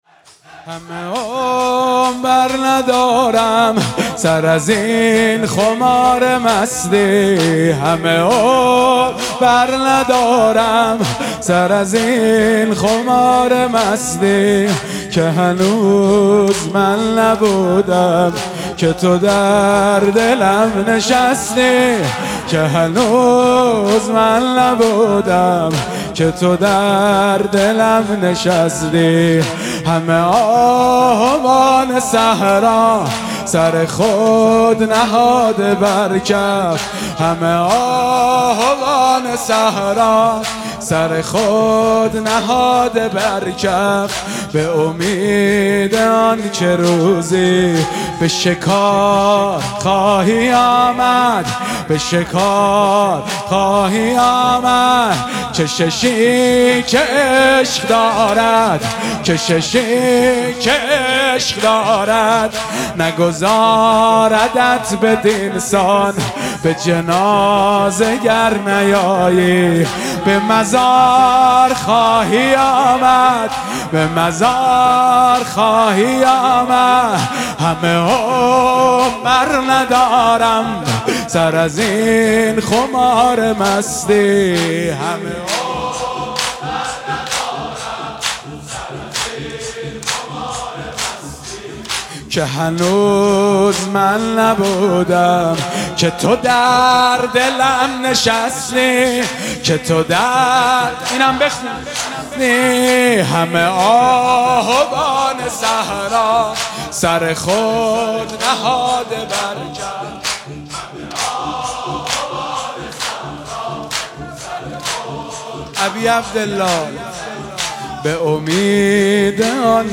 مداحی شب سوم محرم
در هیئت عبدالله بن الحسن